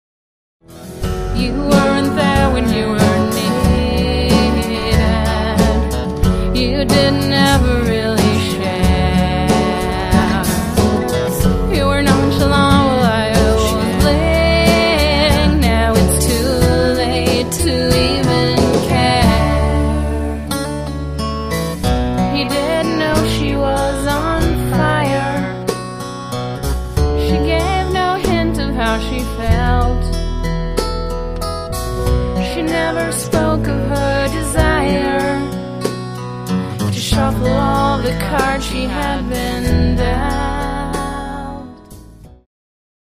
lead vocal, acoustic guitar